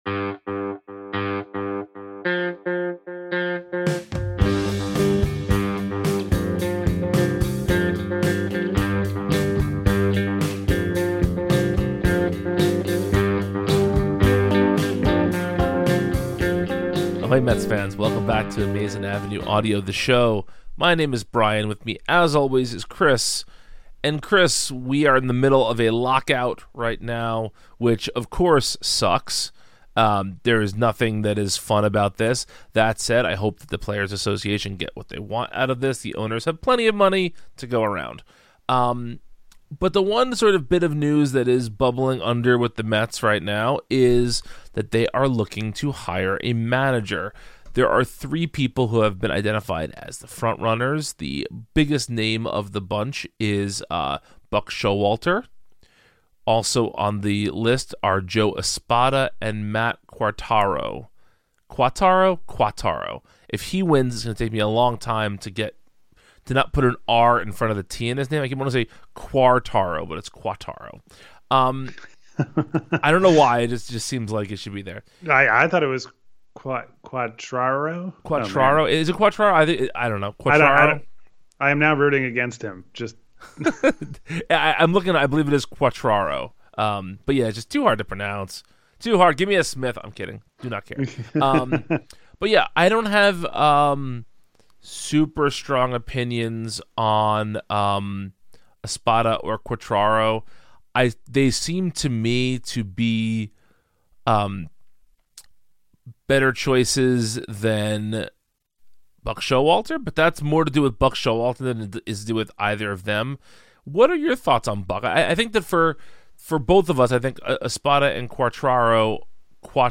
Plus, we pay tribute to Pedro Feliciano and are joined for a quick chat by Trevor May.